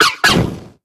Audio / SE / Cries / FENNEKIN.ogg
FENNEKIN.ogg